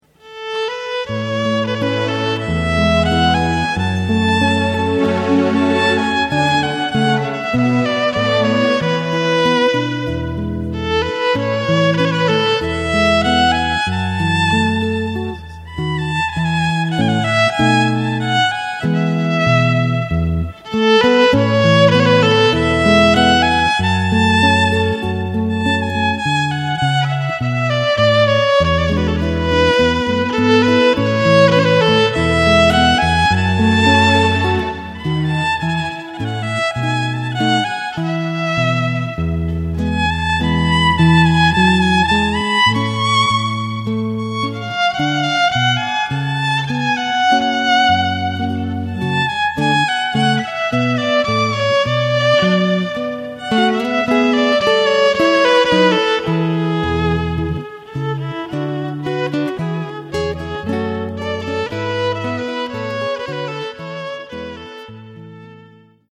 MÚSICA PARA CEREMONIAS RELIGIOSAS O CIVILES
Ensamble: Guitarra & Violín